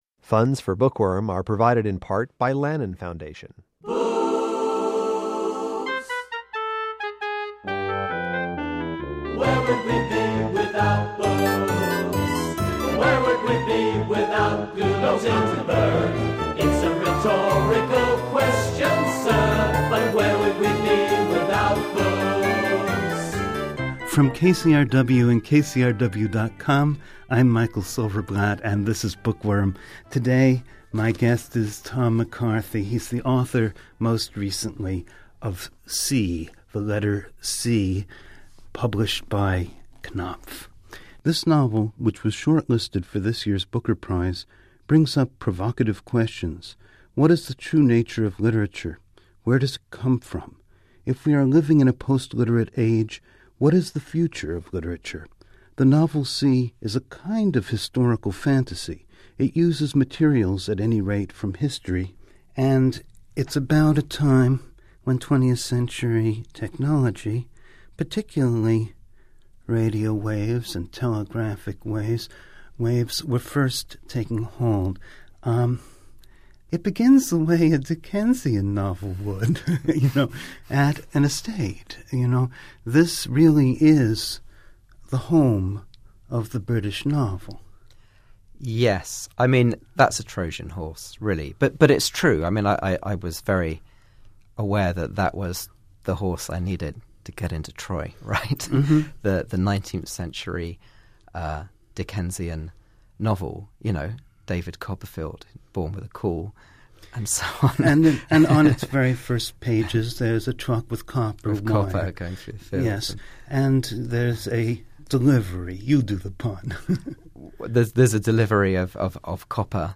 This conversation takes us into the twists and turns of a brainiac's ingenuity. We stop to take a shrewd assessment of the use of this kind of novel in a post-literate culture.